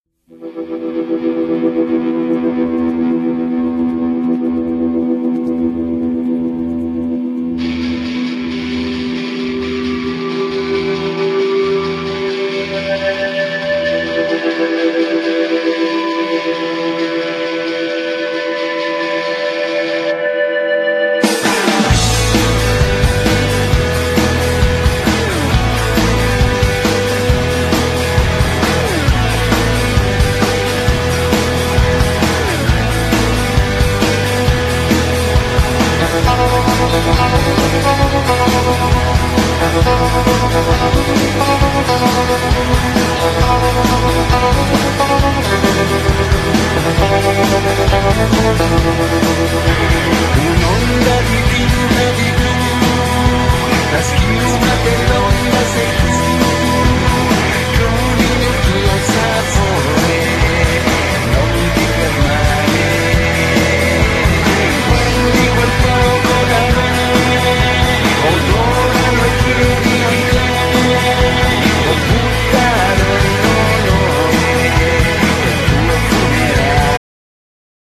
Genere : Pop